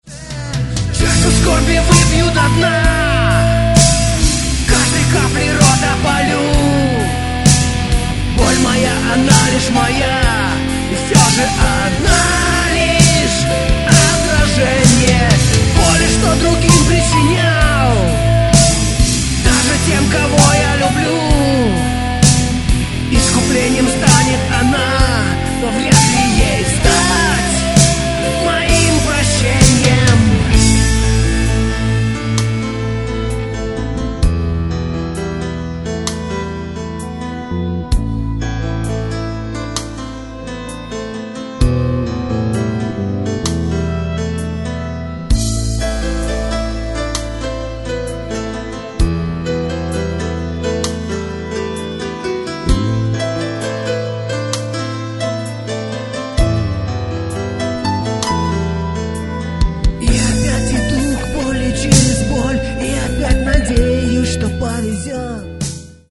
Гитары, бас, клавиши, перкуссия, голос